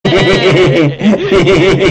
Cheap pickle giggle
cheap-pickle-giggle.mp3